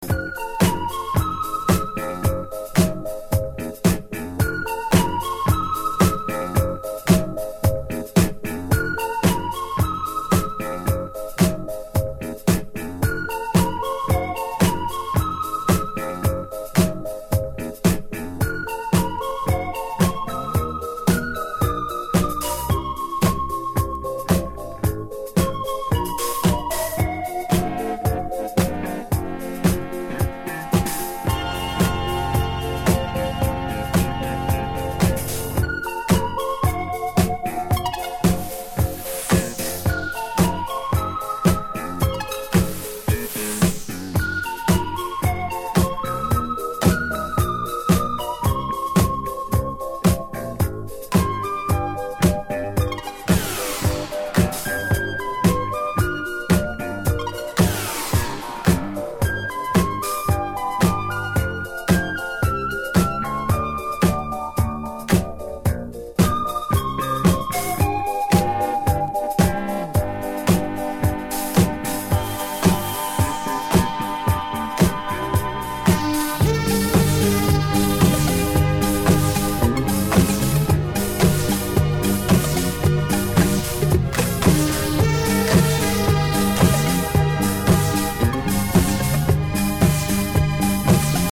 ディスコやブギー好きはもちろん、オブスキュア〜バレアリック、テクノ系のDJにもオススメです！